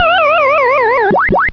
Correct 8-bit wavs to 16-bit